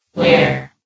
S.P.L.U.R.T-Station-13 / sound / vox_fem / where.ogg
New & Fixed AI VOX Sound Files